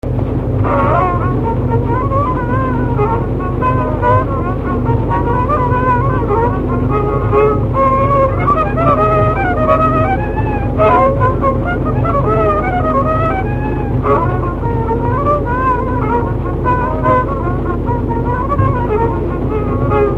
Dallampélda: Hangszeres felvétel
Erdély - Csík vm. - Csíkszenttamás
Műfaj: Sebes csárdás
Stílus: 1.1. Ereszkedő kvintváltó pentaton dallamok
Kadencia: 5 (5) b3 1